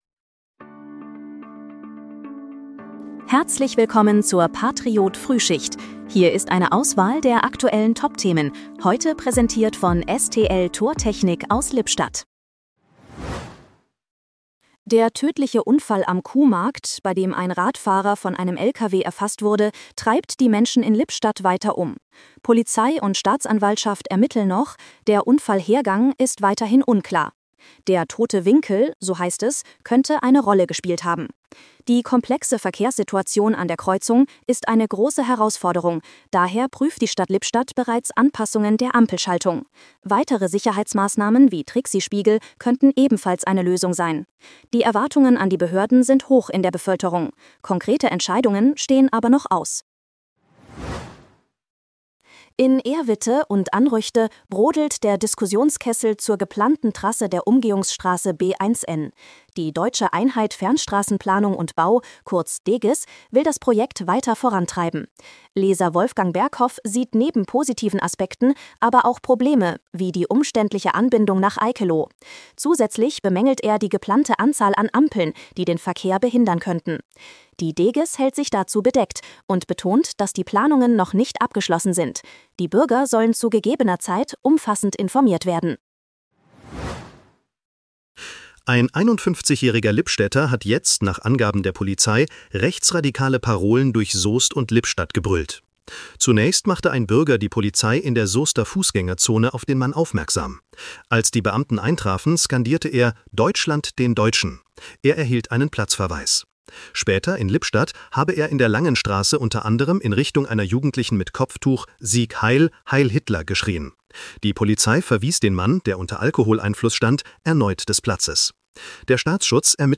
Dein morgendliches News-Update